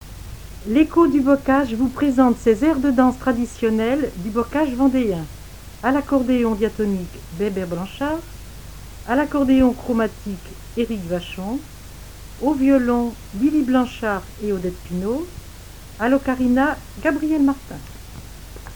Enquête Arexcpo en Vendée-Association Héritage-C.C. Herbiers
Catégorie Témoignage